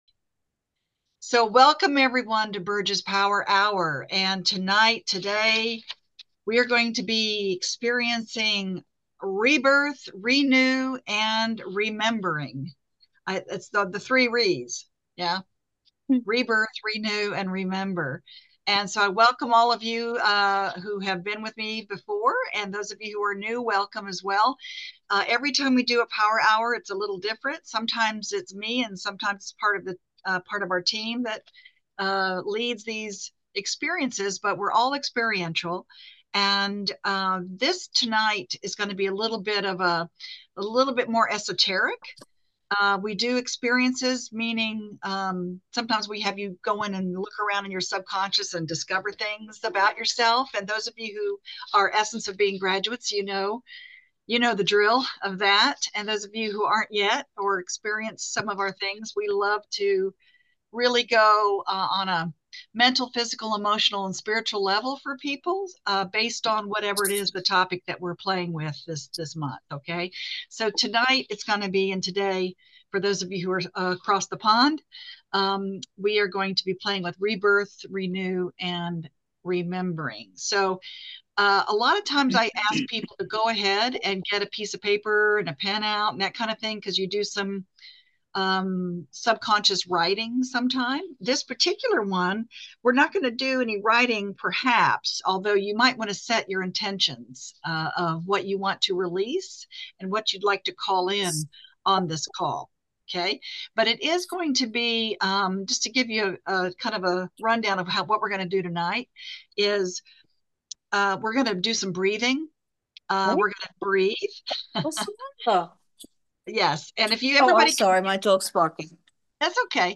Through intimate sharing, breathwork, and energetic insights, we uncover what it means to let go of attachment without losing connection. One woman’s story of recent loss opens a powerful conversation about grief, spiritual presence, and reclaiming life force. This episode offers space for renewal, a deeper understanding of what it means to truly remember who we are, and a guided meditation to anchor in the energy of rebirth.